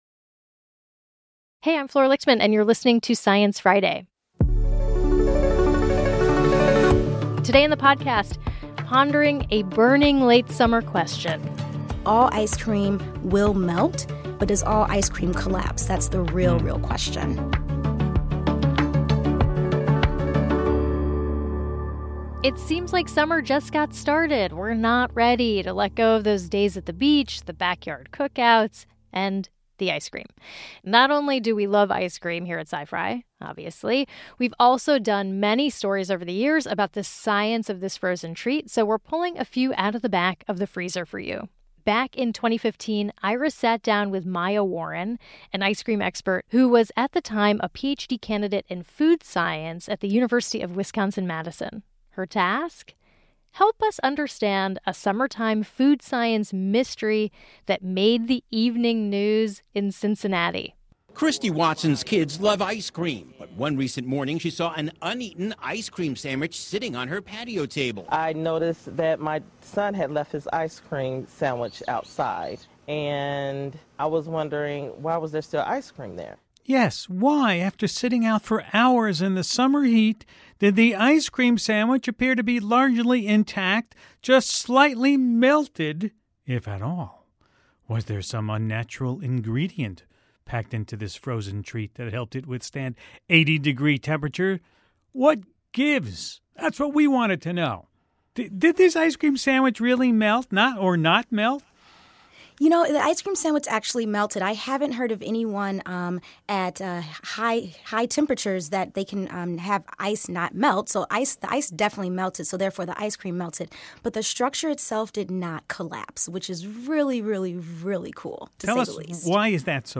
sat down with Host Ira Flatow